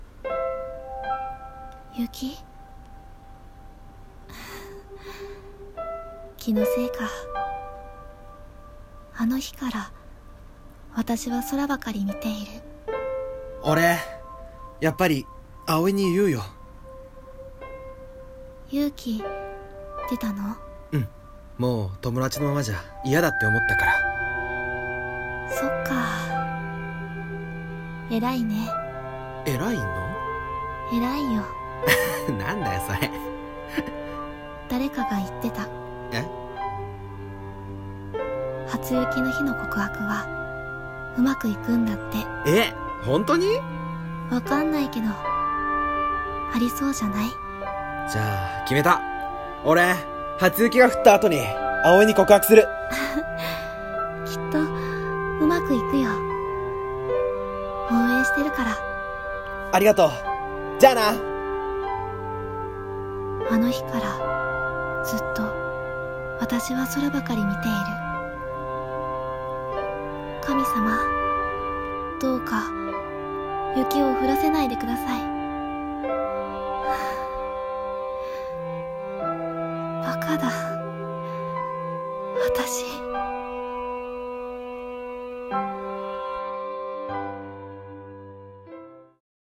【声劇台本】初雪